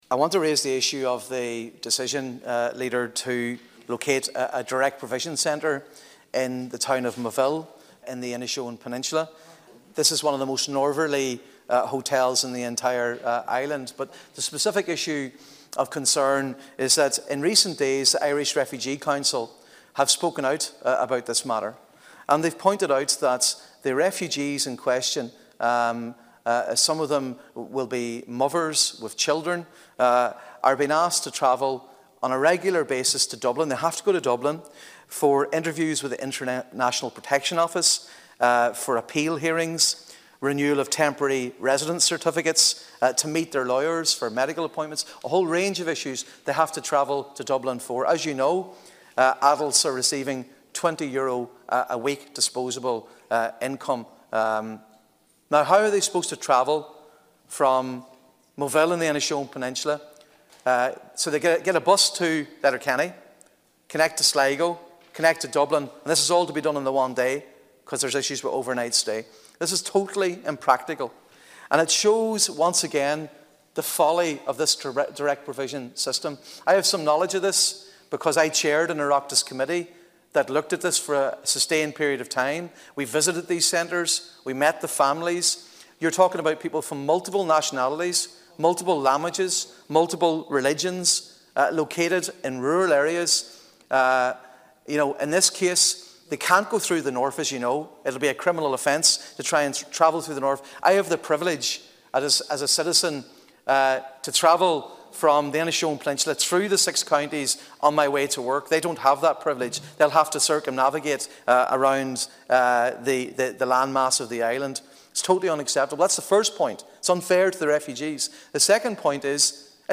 Senator Padraig Mac Lochlainn told the house the core of the problem is that because of restrictions on peoples’ movement, transport to Dublin will be a major issue, and it seems that’s something that hasn’t been factored into the planning…………